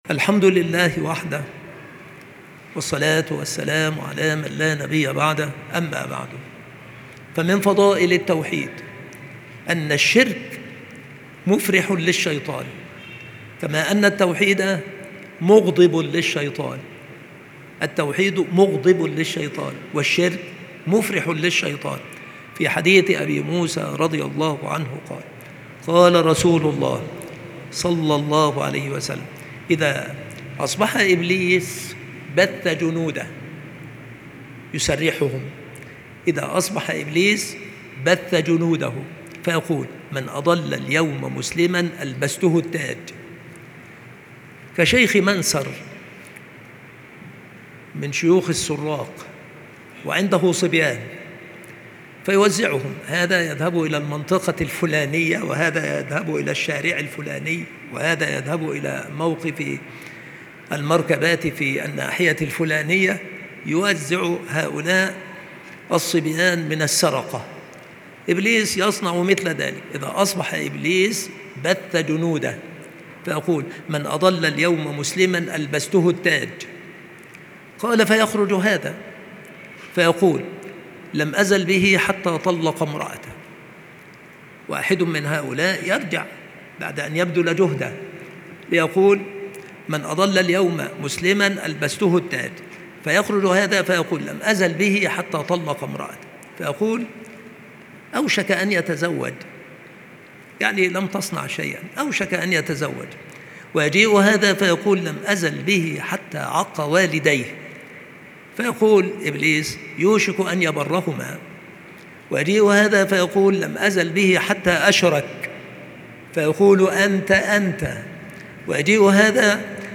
مكان إلقاء هذه المحاضرة بالمسجد الشرقي - سبك الأحد - أشمون - محافظة المنوفية - مصر